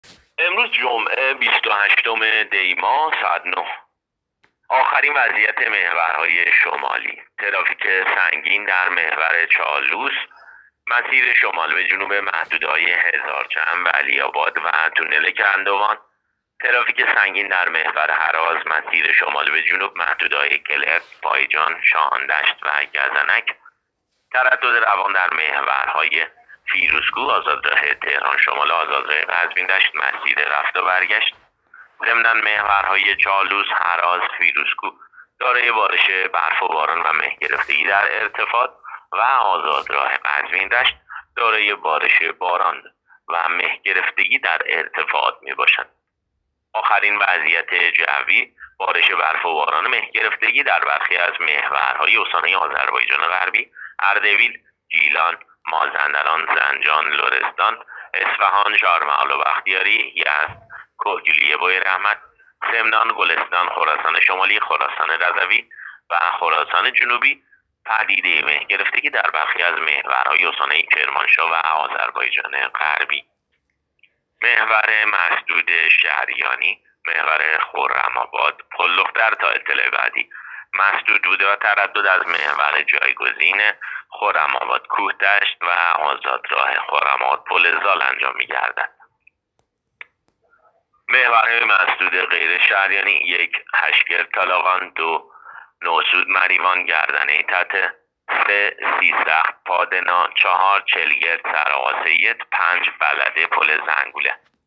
گزارش رادیو اینترنتی از آخرین وضعیت ترافیکی جاده‌ها تا ساعت ۹ بیست و هشتم دی؛